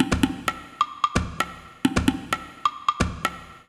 130_perc_1.wav